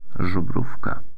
Żubrówka Bison Grass Vodka (Polish pronunciation: [ʐuˈbrufka]
Pl-Żubrówka.ogg.mp3